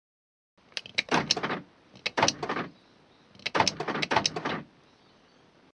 Car Door Lock Sound Buttons
car-door-lock-48006.mp3